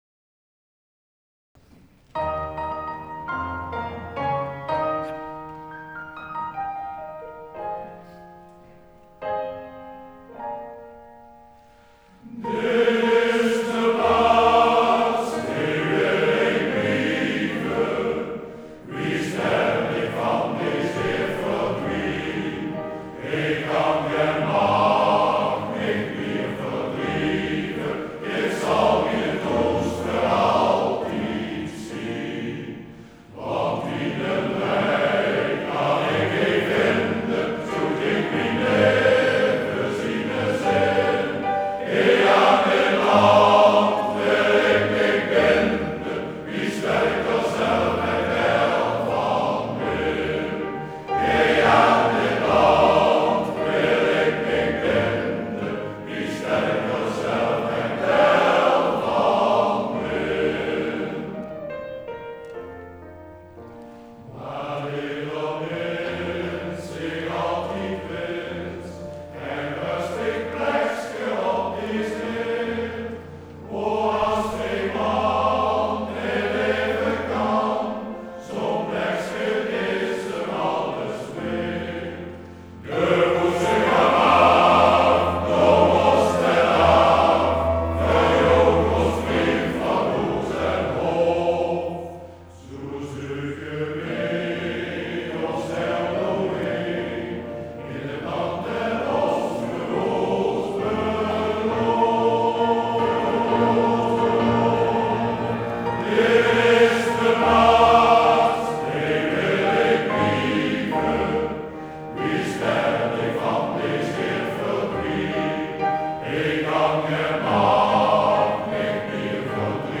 Koninklijke Zangvereniging Venlona - Luisteren
Opname Maaspoort 2011